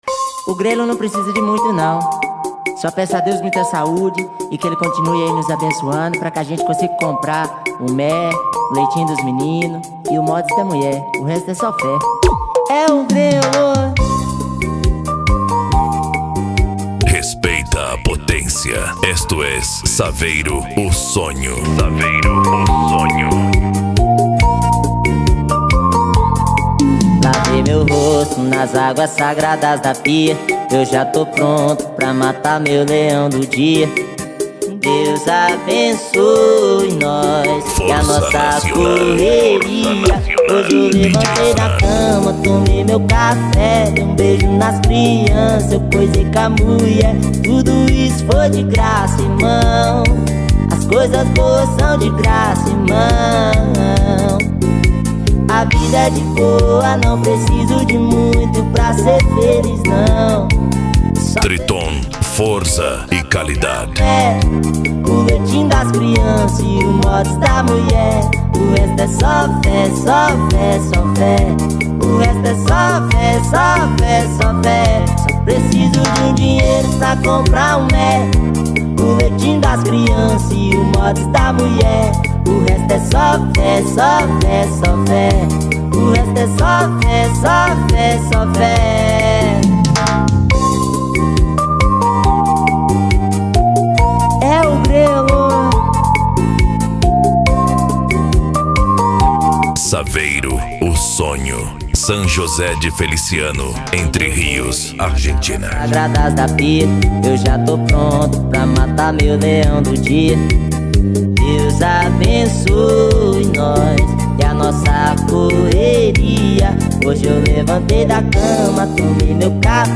Bass
Funk
SERTANEJO